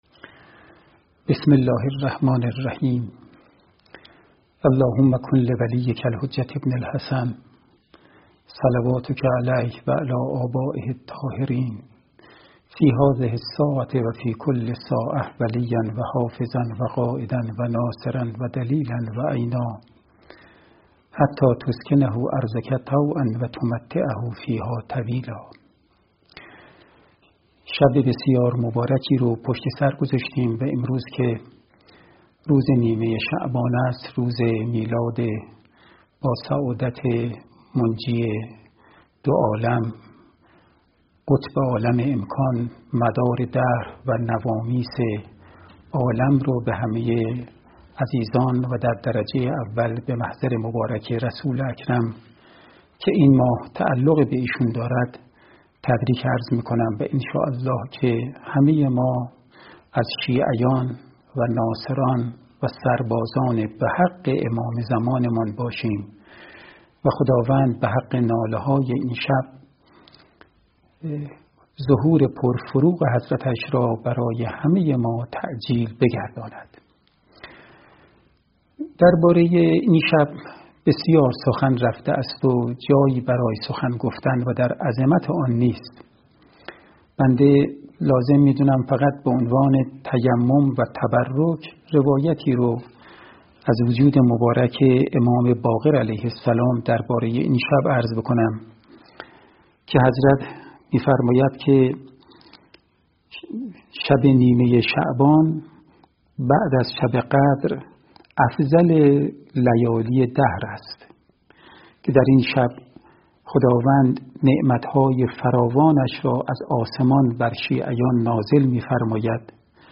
سخنرانی
در روز نیمه شعبان 1399 به صورت زنده از پایگاه نمو پخش شد.